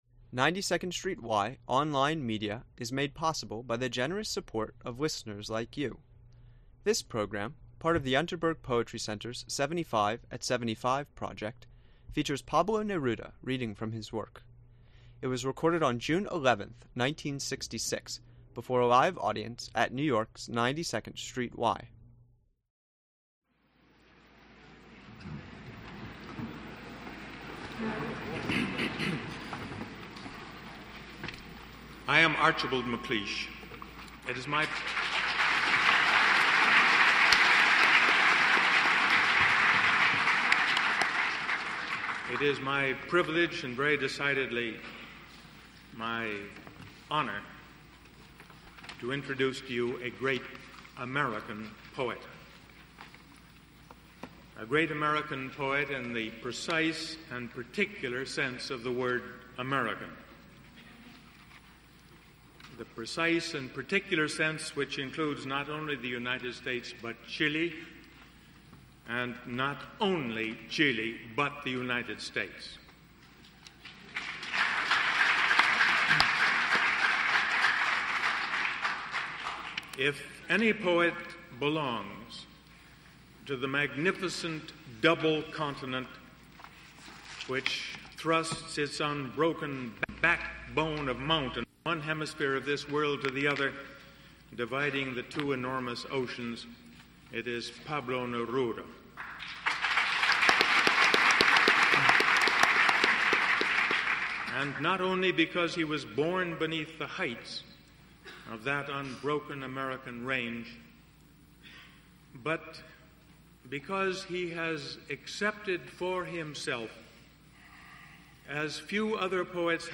Pablo Neruda's Historic First Reading in the US (1966) | { Archipelago Commons 1.4.0 }